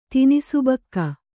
♪ tinisubakka